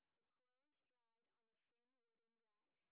sp15_street_snr30.wav